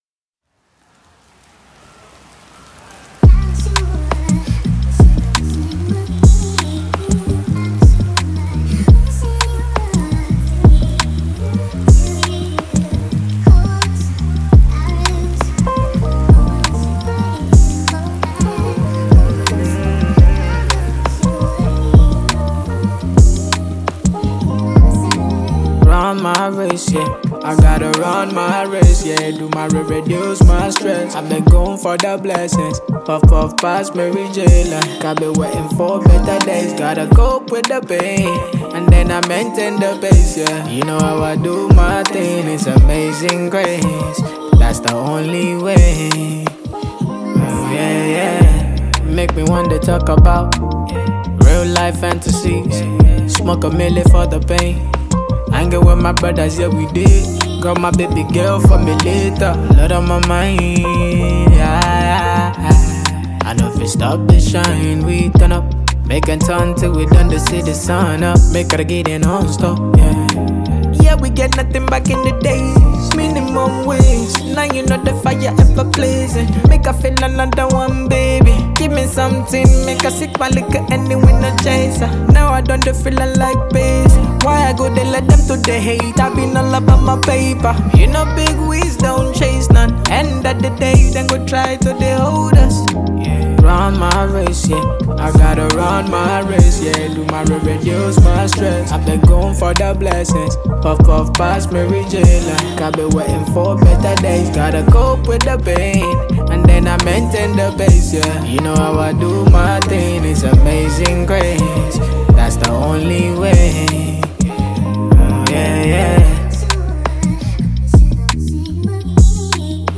Grammy Award winning Nigerian heavyweight Afrobeat Singer